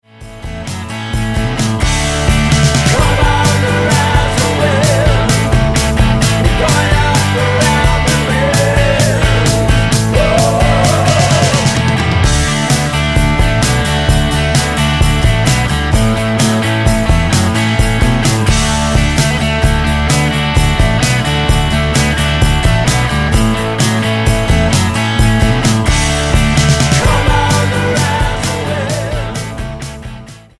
Tonart:D mit Chor
Die besten Playbacks Instrumentals und Karaoke Versionen .